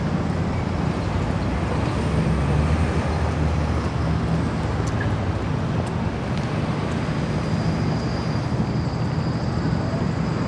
AMBIENT
1 channel
CITY1.WAV